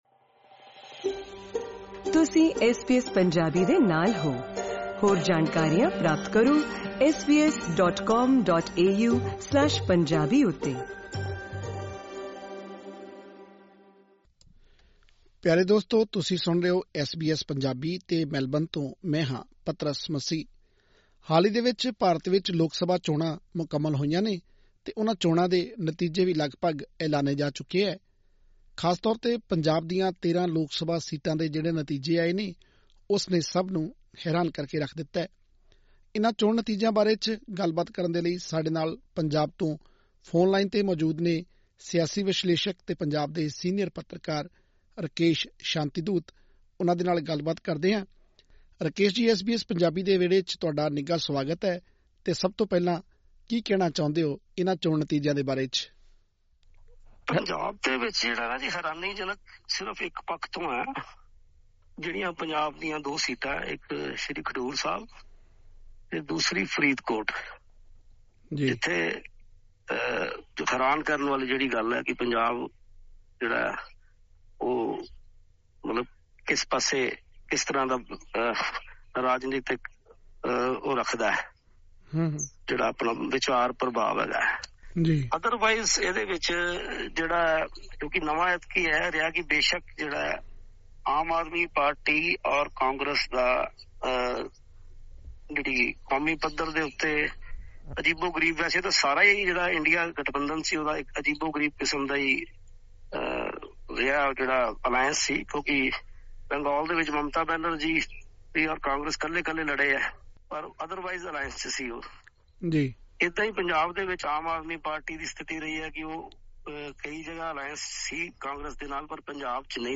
ਉਨ੍ਹਾਂ ਕਿਹਾ ਕਿ ਕੌਮੀ ਪੱਧਰ ’ਤੇ ਜਿਸ ਤਰ੍ਹਾਂ ਦੇ ਸਮੀਕਰਨ ਬਣੇ ਹਨ, ਉਨ੍ਹਾਂ ਤੋਂ ਸਪੱਸ਼ਟ ਹੈ ਨਰੇਂਦਰ ਮੋਦੀ ਲਈ ਬਤੌਰ ਪ੍ਰਧਾਨ ਮੰਤਰੀ ਇਸ ਵਾਰ ਸਰਕਾਰ ਚਲਾਉਣਾ ਆਸਾਨ ਨਹੀਂ ਹੋਵੇਗਾ ਅਤੇ ਇਸ ਸਭ ਦਾ ਸਿੱਧਾ ਅਸਰ ਭਾਰਤ ਦੇ ਹੋਰਨਾਂ ਮੁਲਕਾਂ ਨਾਲ ਸਬੰਧਾਂ ’ਤੇ ਵੀ ਪਵੇਗਾ। ਹੋਰ ਵੇਰਵੇ ਲਈ ਸੁਣੋ ਇਹ ਇੰਟਰਵਿਊ.....